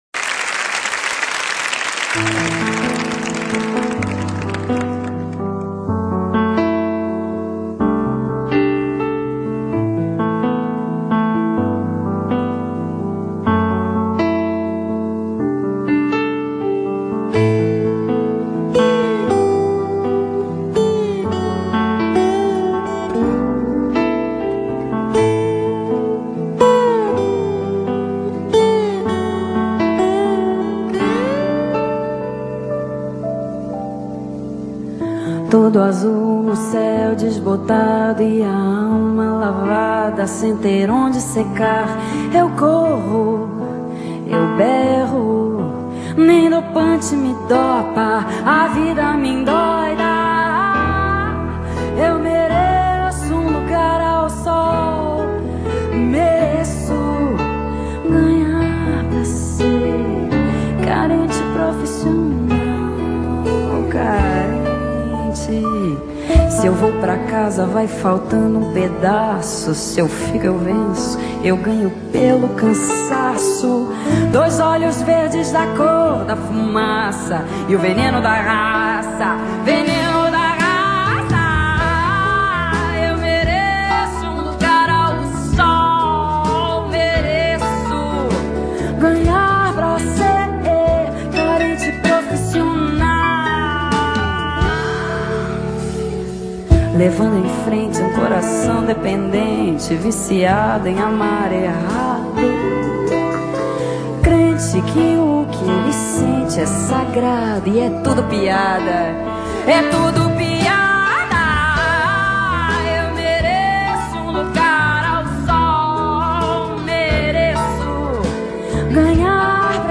MPB, Folk, Indie, Indie-rock, Samba tropicalista,